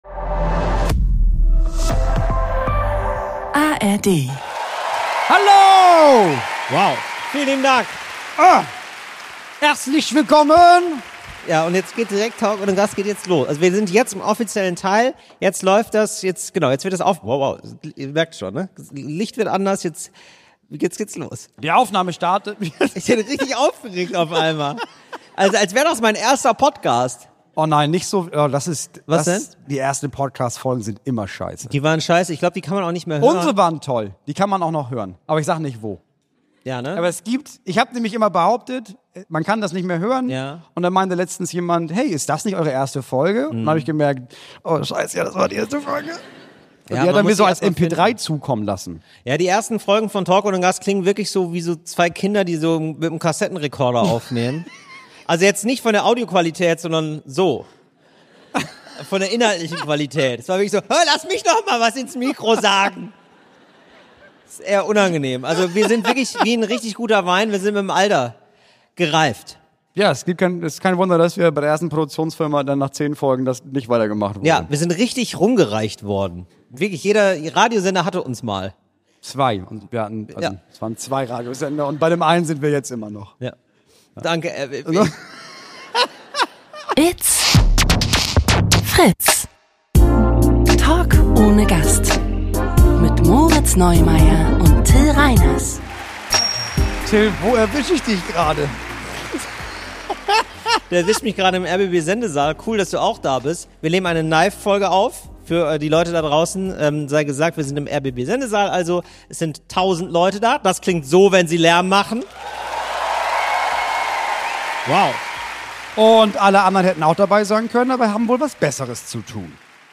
Beschreibung vor 1 Woche Heute in eurem 360-Grad-Adventstürchen: Eine Live-Folge!